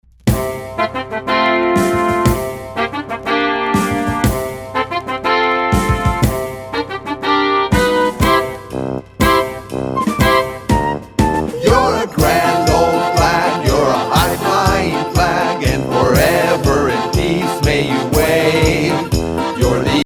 Downloadable Musical Play with Album Sheet Music.